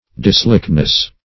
Dislikeness \Dis*like"ness\, n.